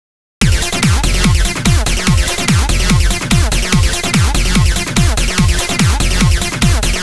以下、EQ処理をする前としたあとのデモ音源です。
【EQ処理前】
ひとまずここで着目してもらいたい点はアシッド音(なんかウニョウニョなってる電子音)です。